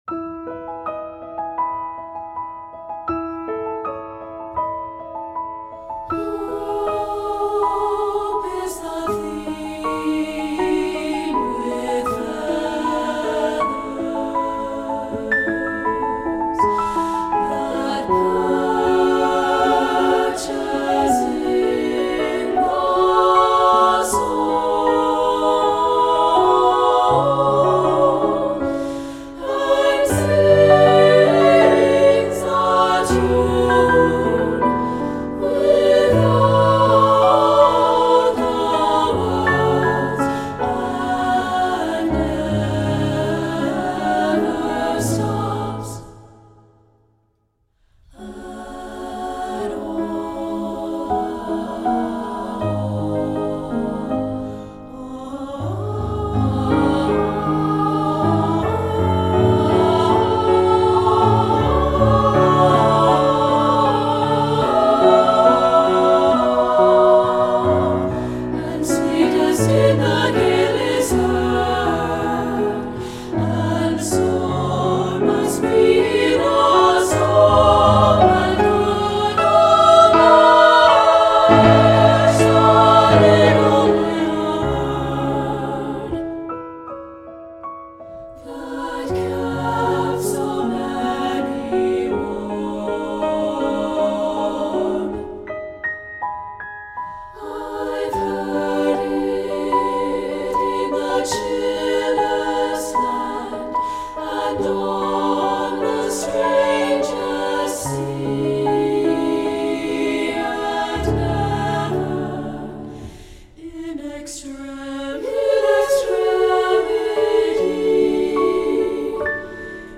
SSAA recording